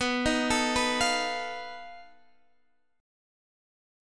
B7b5 Chord
Listen to B7b5 strummed